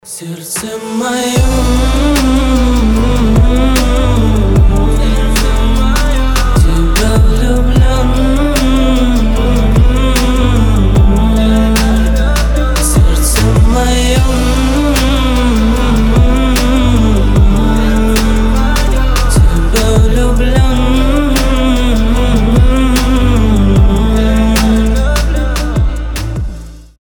мужской голос
лирика